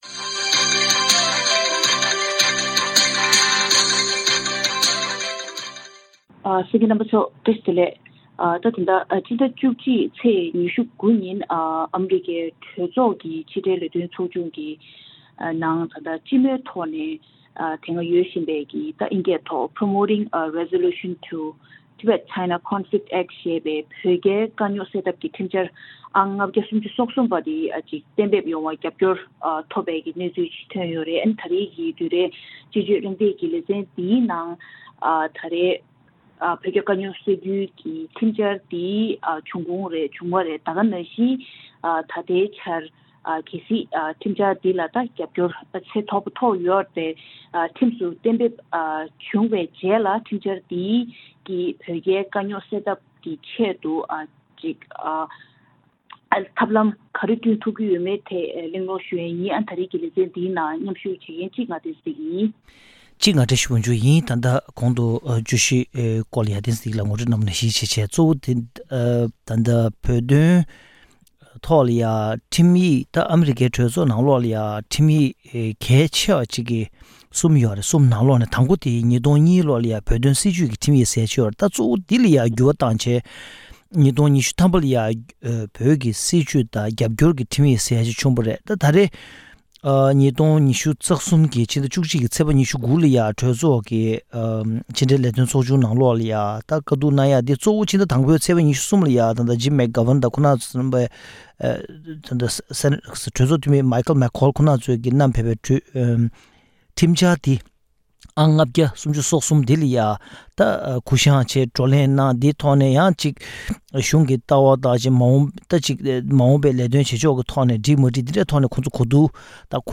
དཔྱད་བརྗོད་གླེང་སྟེགས་ཀྱི་ལས་རིམ།